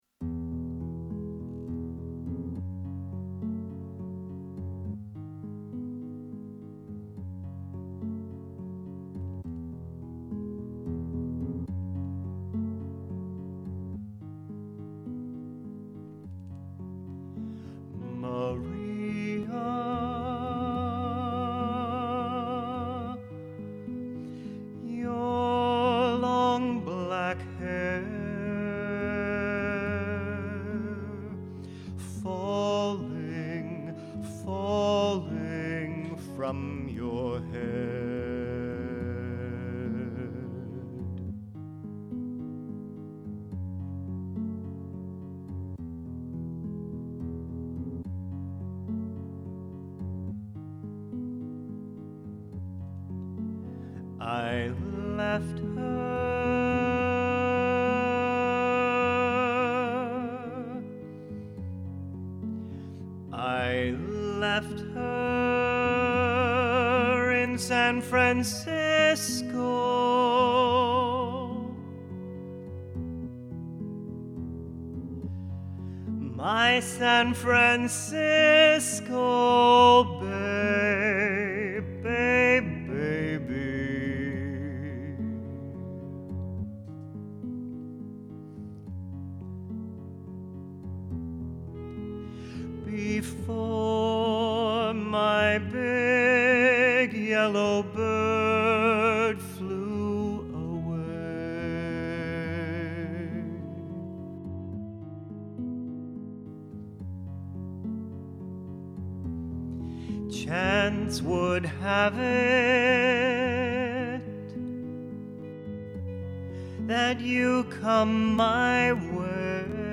Maria (Vocals